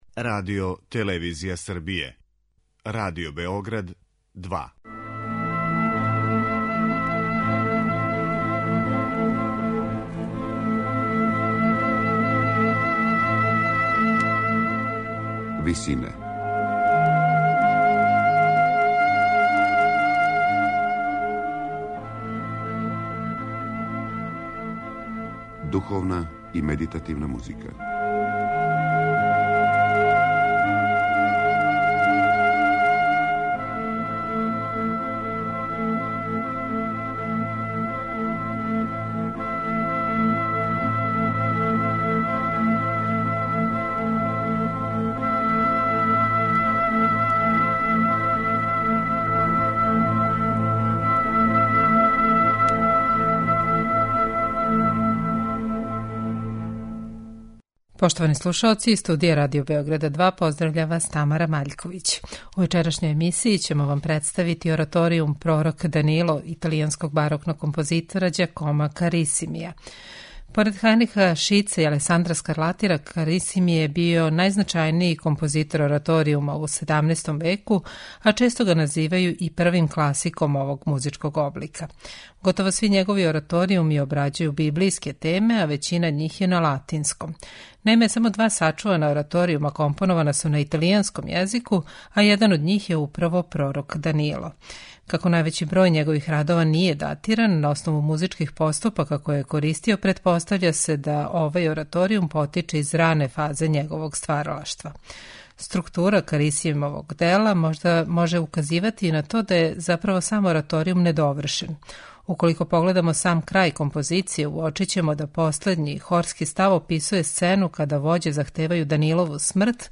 У вечерашњој емисији слушаћете ораторијум 'Пророк Данило', италијанског барокног композитора Ђакома Карисимија.
бас
контратенор
сопрани
тенор